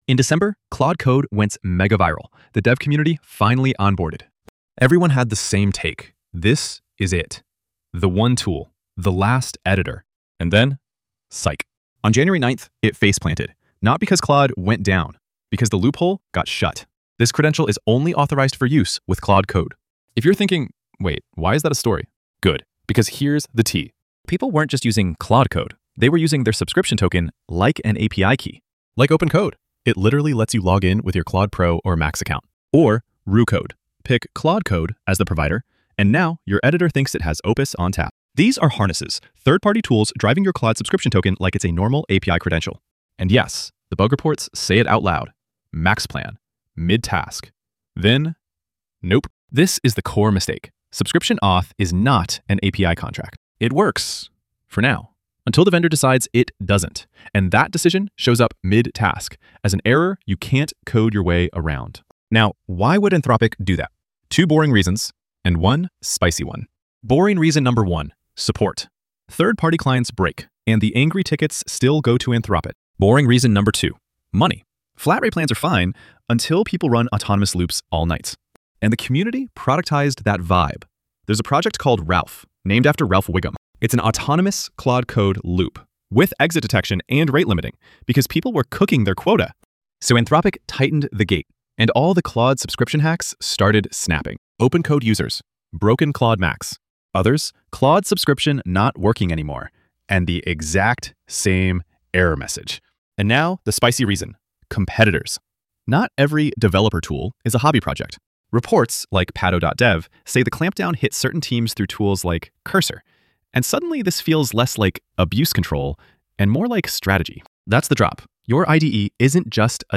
Voiceover
The script mostly maintains a brisk, Fireship-style pace, but several segments are either rushed (cramming punchlines or key info) or draggy (diluting impact).
The slowest segments risk losing energy, while the fastest ones risk losing clarity or comedic timing.